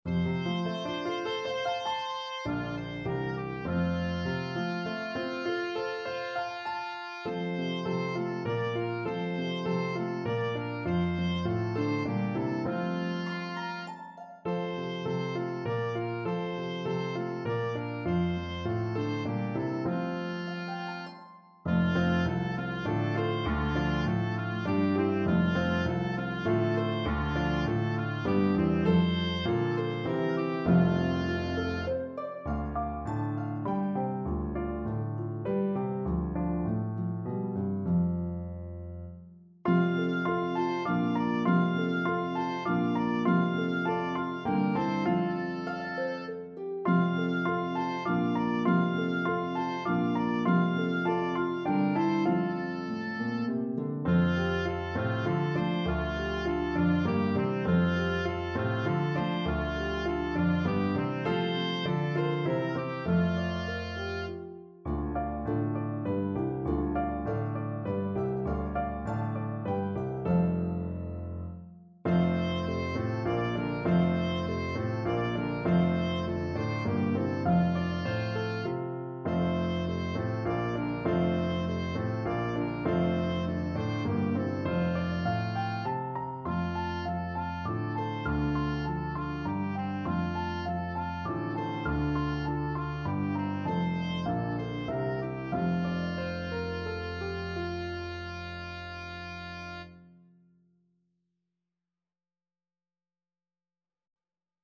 Their trip to the manger is rhythmically interesting!